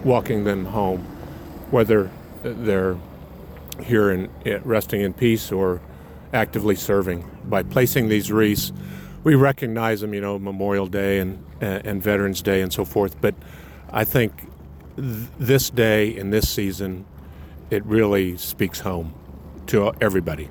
Offering remarks Saturday was 60th District Kansas Representative Mark Schreiber, who says while other ceremonies honor simply the service and sacrifice of veterans, Wreaths Across America serves not only as a time of remembrance and honor, but also a homecoming.